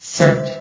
S.P.L.U.R.T-Station-13/sound/vox_fem/third.ogg
* New & Fixed AI VOX Sound Files